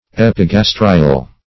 Epigastrial \Ep`i*gas"tri*al\, a.
epigastrial.mp3